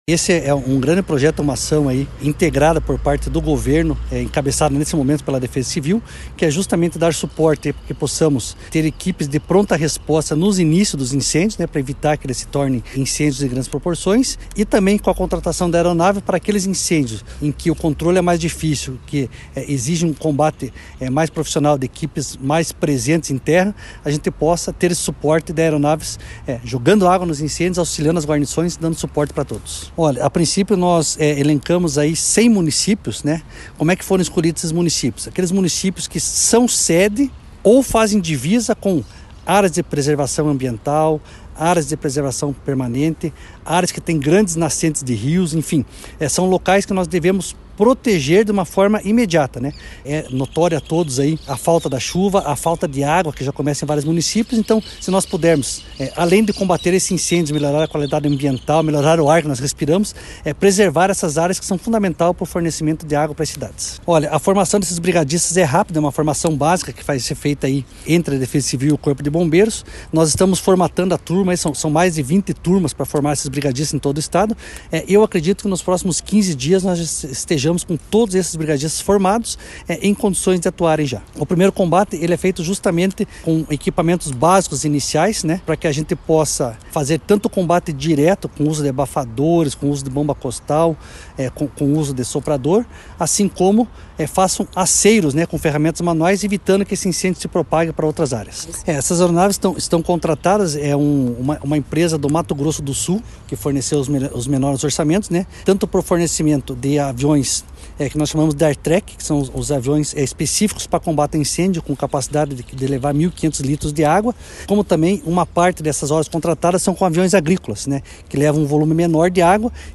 Sonora do coordenador estadual da Defesa Civil, coronel Fernando Schunig, sobre a contratação de aviões para combate a incêndios no Paraná